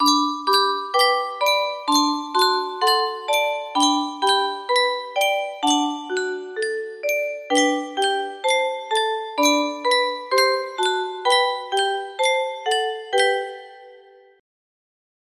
Yunsheng Music Box - Jolly Old St. Nicholas Y214 music box melody
Full range 60